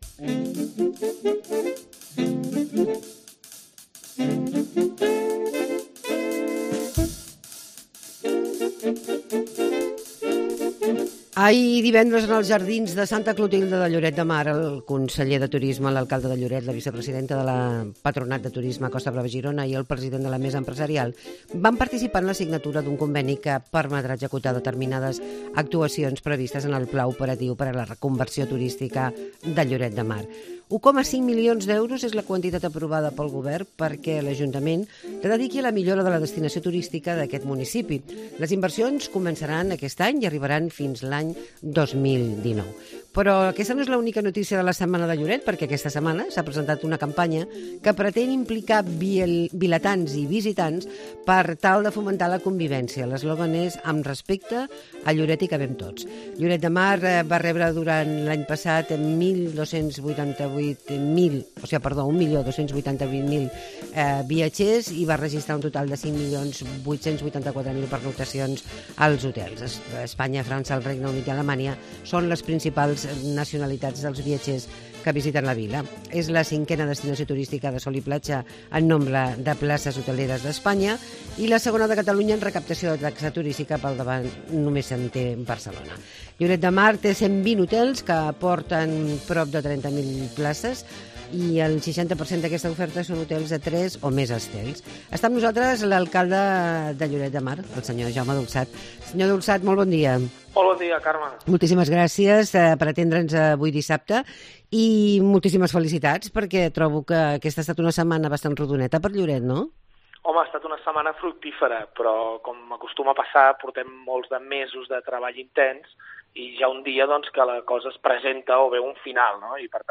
Entrevista amb l'alcalde de Lloret, Jaume Dulsat